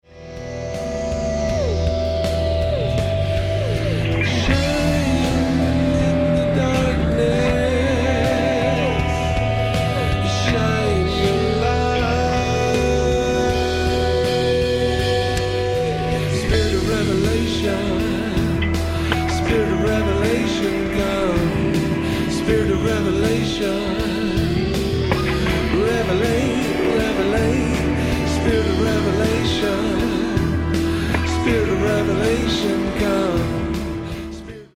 a broad spectrum of musical sounds